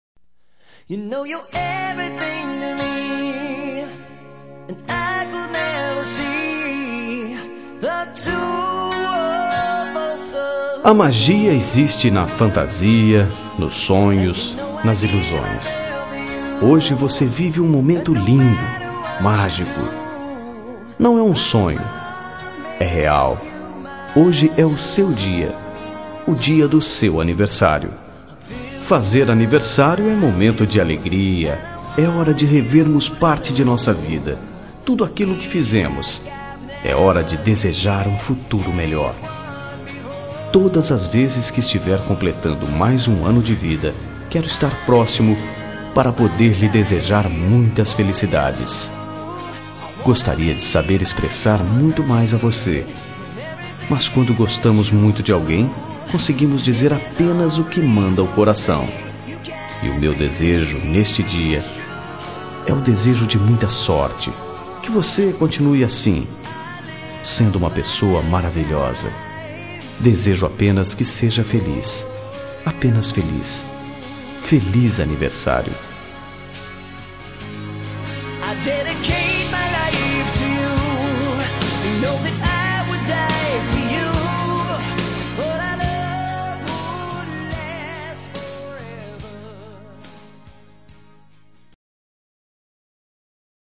Telemensagem Aniversário de Paquera -Voz Masculina – Cód: 1271 – Linda